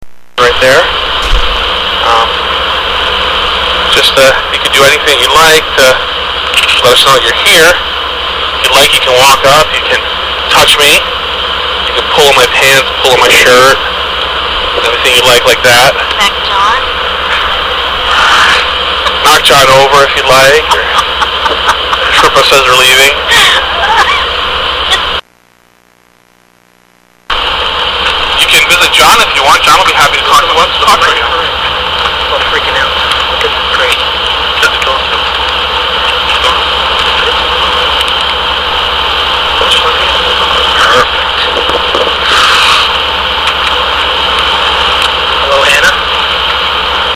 Pictures and E.V.P.'s